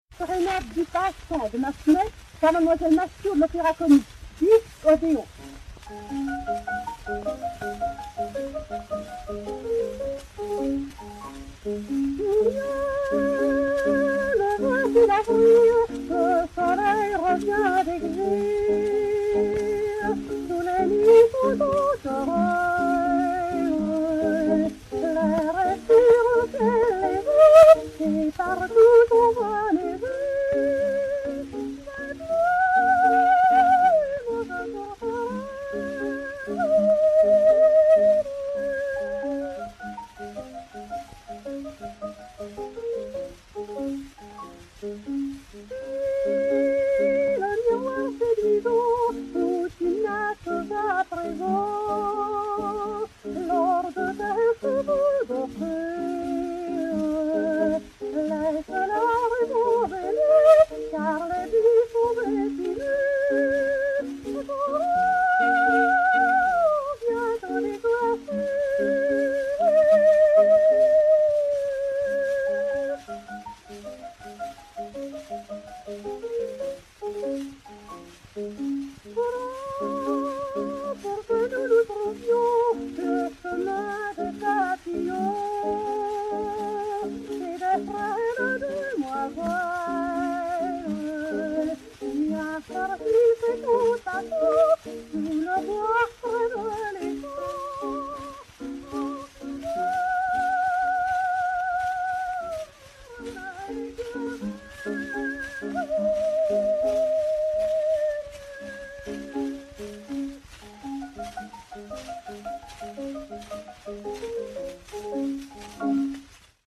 soprano, avec piano
Odéon X 33589, enr. à Paris en janvier 1904